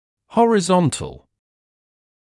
[ˌhɔrɪ’zɔntl][ˌхори’зонтл]горизонтальный